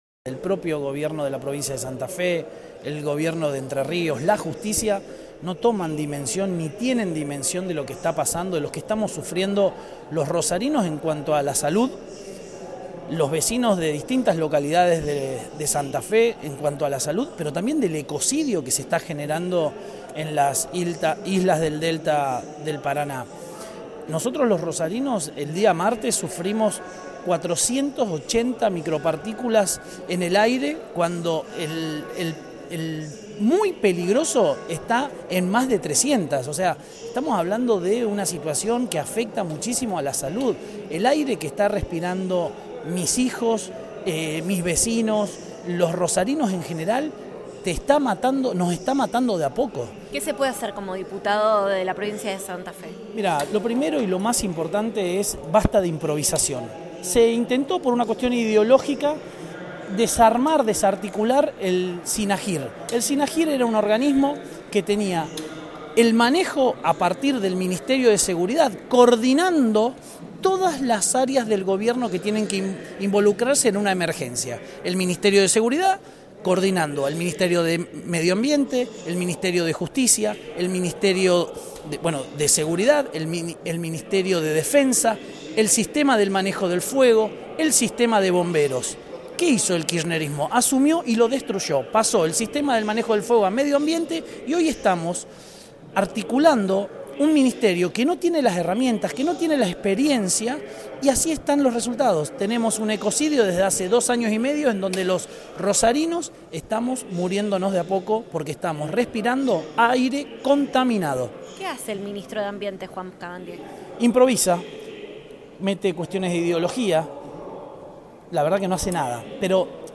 En el marco de la marcha llevada adelante este jueves en el Obelisco de Buenos Aires para reclamar que se detengan los incendios en el Delta del Paraná, Federico Angelini, diputado nacional de la Provincia de Santa Fe, se comunicó con Red Boing para dar su punto de vista tras las quemas y las respuestas brindadas por el gobierno.